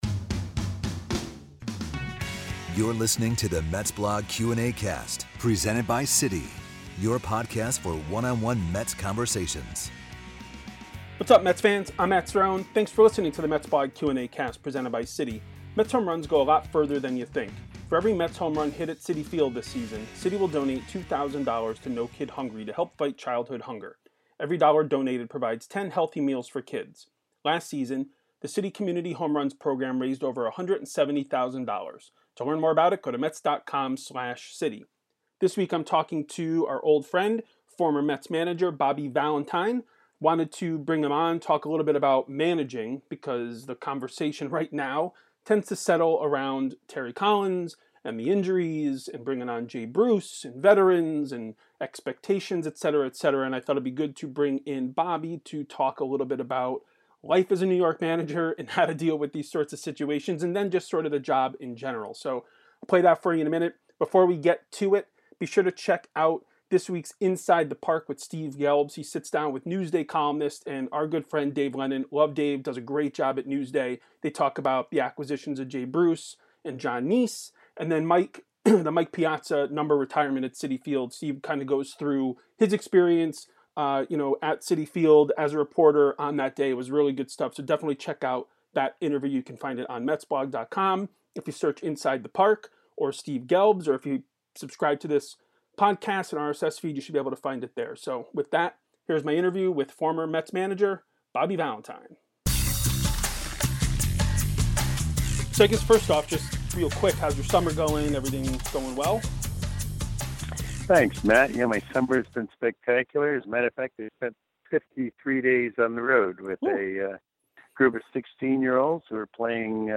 MetsBlog Q&Acast: Bobby Valentine interview